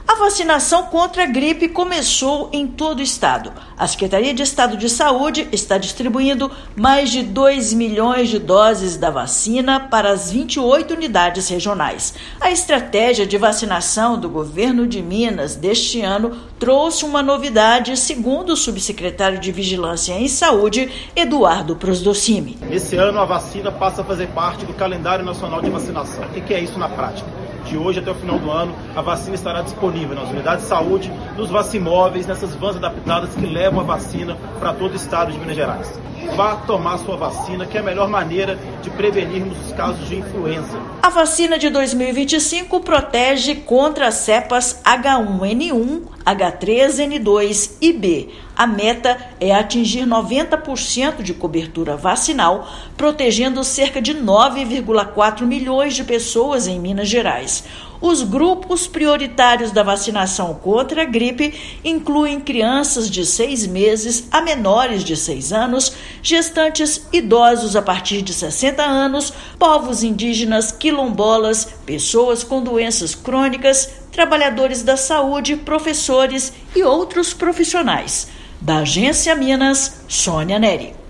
Imunizante passa a integrar Calendário Nacional e poderá ser encontrado nas UBS e vacimóveis durante todo o ano. Ouça matéria de rádio.